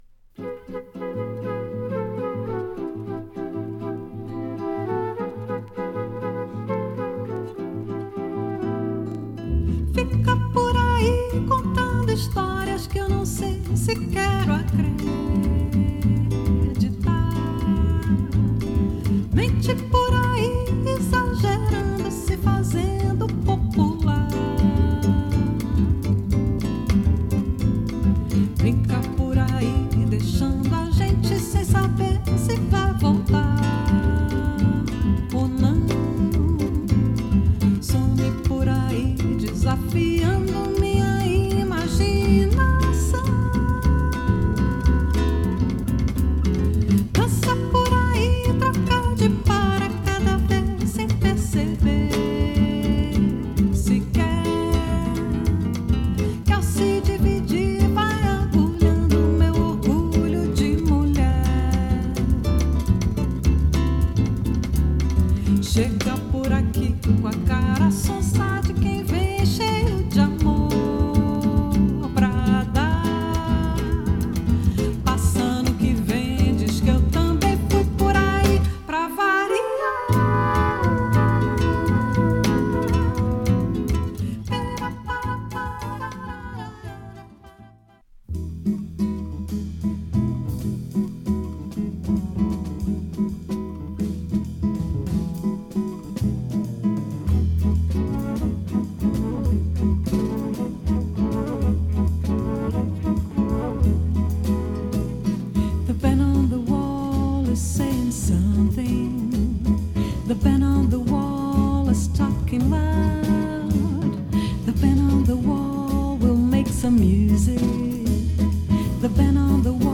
グルーヴ感溢れる
吹き抜けるフルートが印象的なスキャット・ナンバー
躍動感が凄い